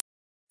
radar.mp3